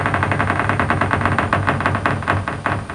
Creaking Door Sound Effect
Download a high-quality creaking door sound effect.
creaking-door.mp3